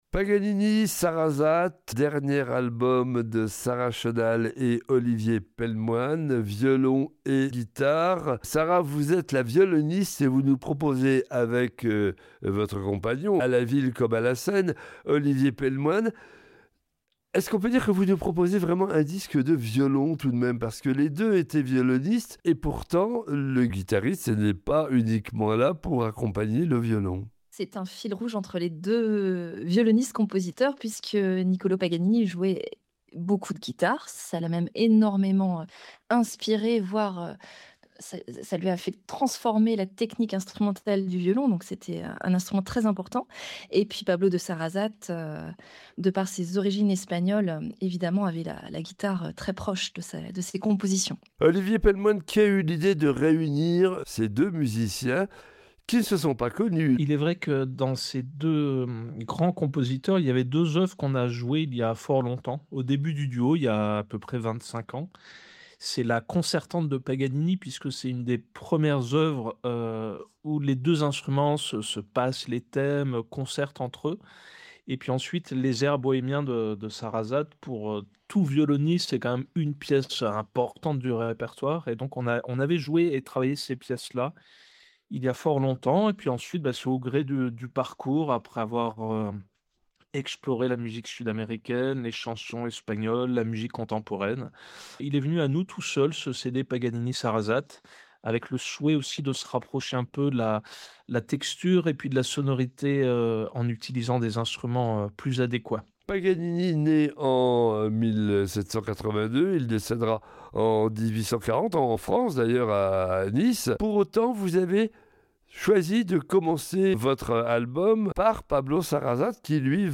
Retrouvez ici l’intégrale des entretiens diffusés par BFC Classique dans son programme diffusé durant la semaine du 20 au 24 avril.